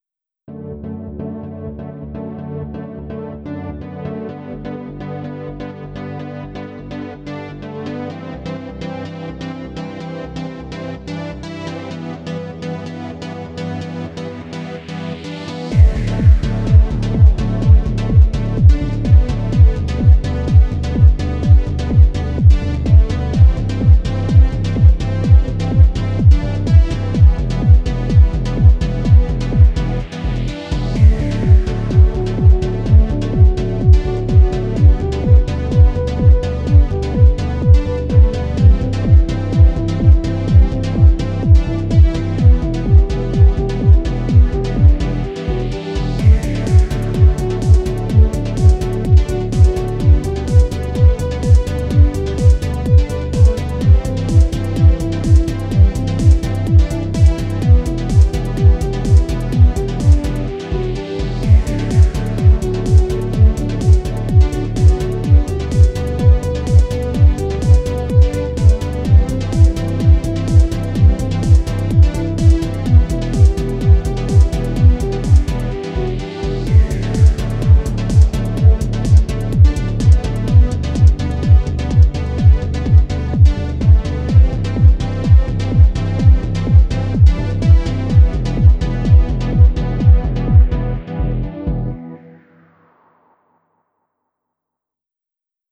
ELECTRO S-Z (34)